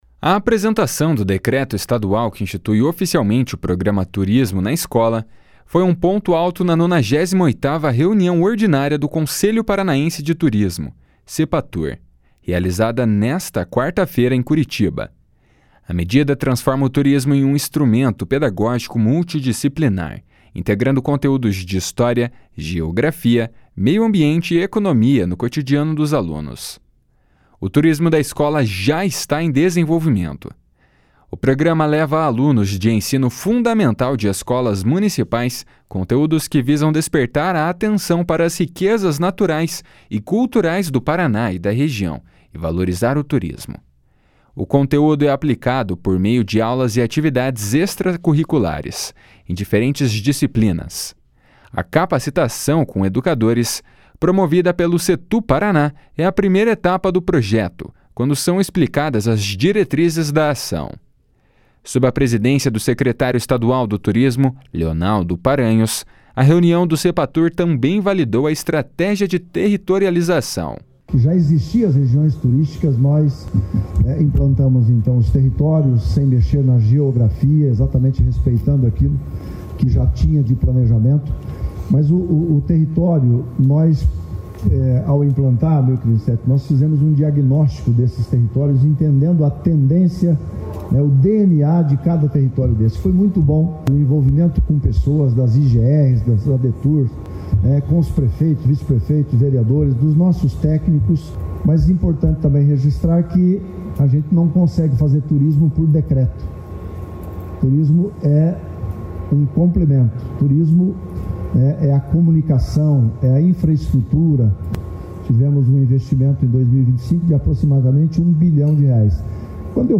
// SONORA LEONALDO PARANHOS //